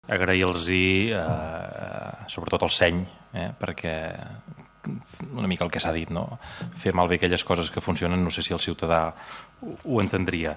Verdaguer al final de la compareixença.